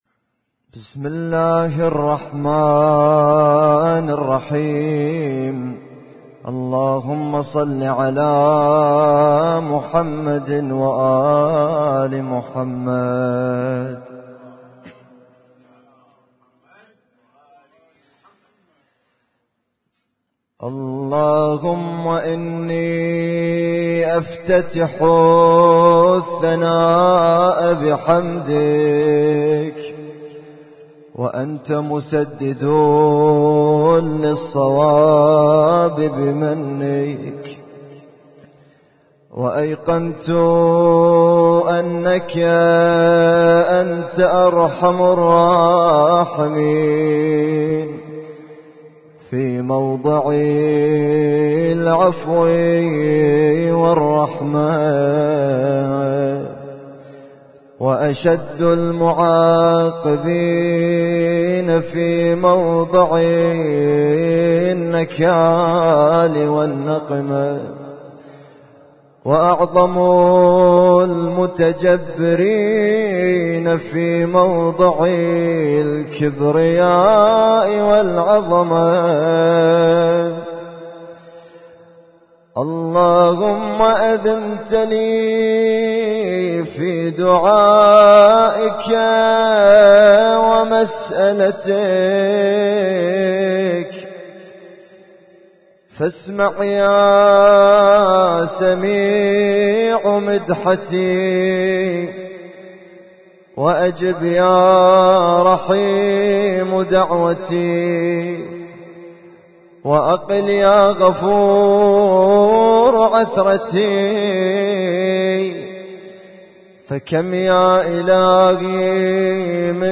Husainyt Alnoor Rumaithiya Kuwait
اسم التصنيف: المـكتبة الصــوتيه >> الادعية >> دعاء الافتتاح